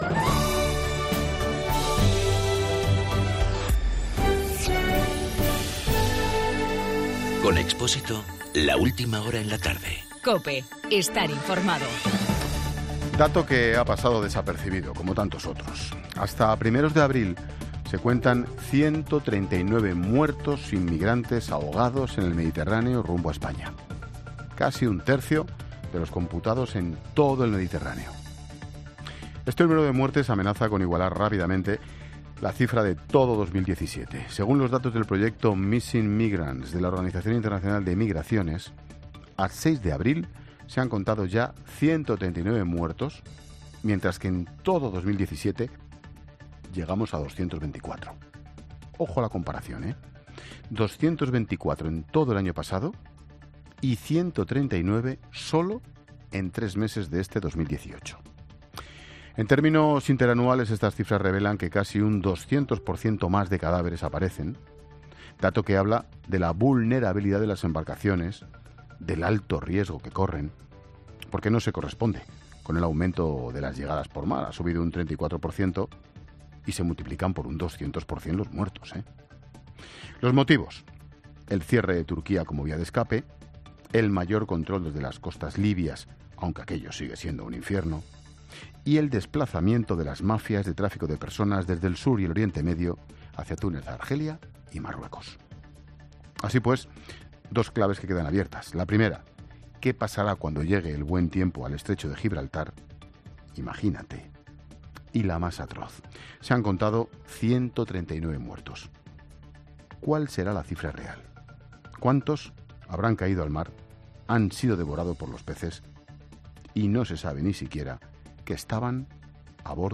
Monólogo de Expósito
El comentario de Ángel Expósito sobre los últimos datos de abril de llegadas de embarcaciones al Mediterráneo.